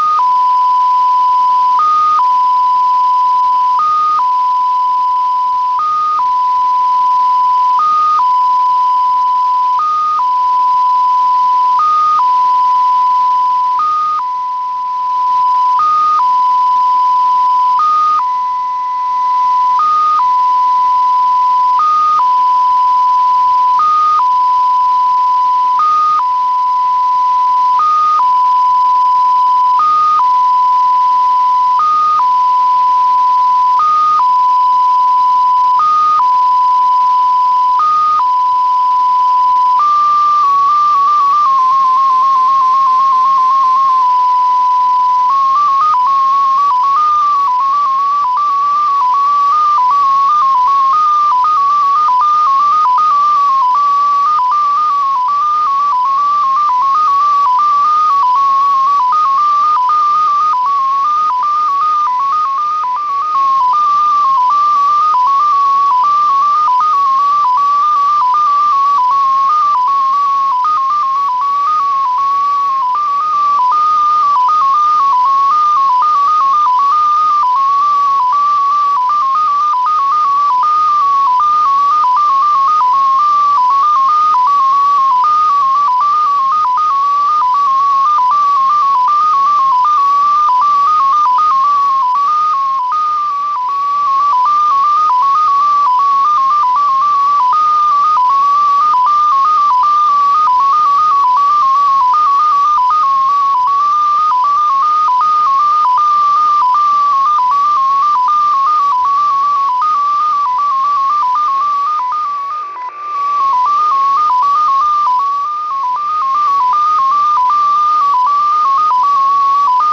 Mode: USB